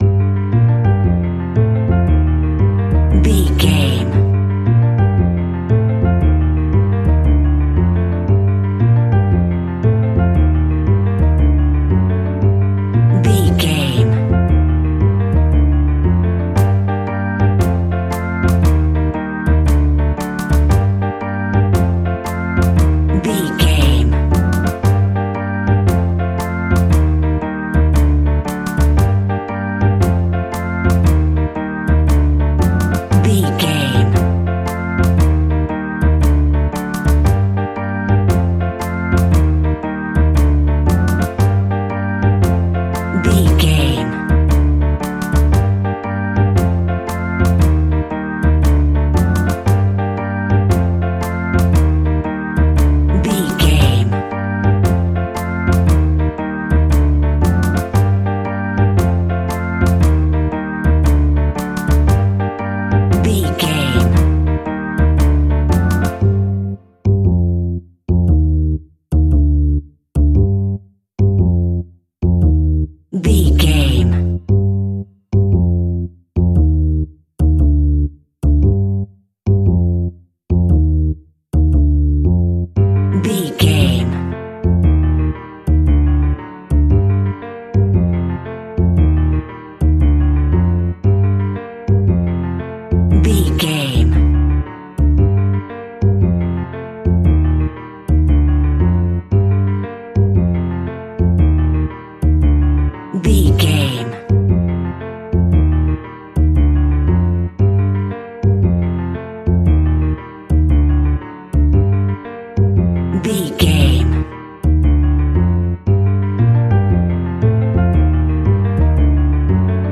Aeolian/Minor
scary
tension
ominous
dark
haunting
eerie
playful
double bass
piano
drums
electric organ
creepy
horror music